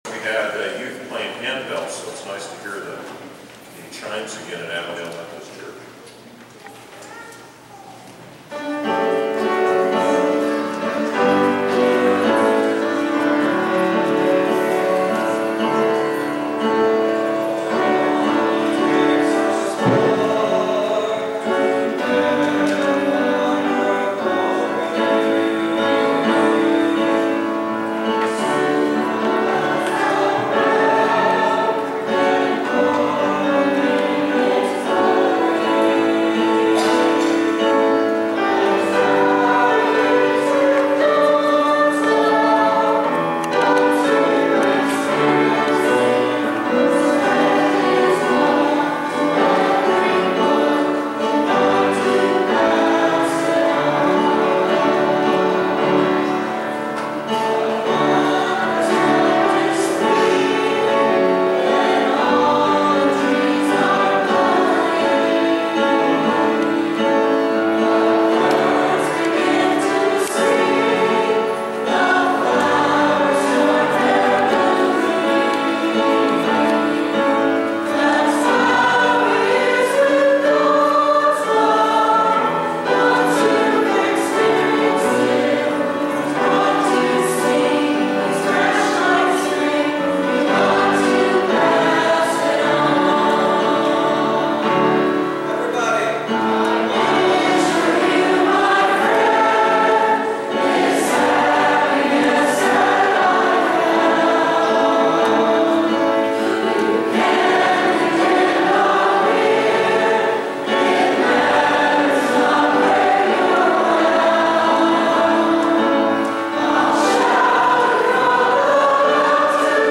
Following the Worship Service and the Anniversary Dinner, the day’s events Sunday concluded with a Music Celebration service.
Various vocals and instrumentals, performed by people from the youngest to the more mature were featured.
The “Tell it Like it is” Reunion Group did “Pass it On”